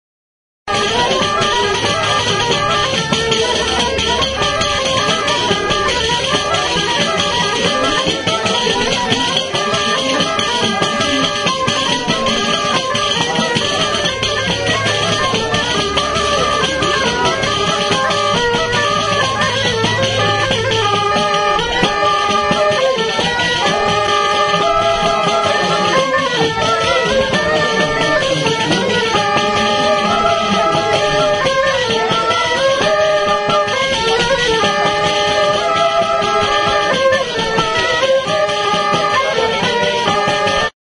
Aerófonos -> Lengüetas -> Simple (clarinete)
JOTA.
ALBOKA
Klarinete bikoitza da.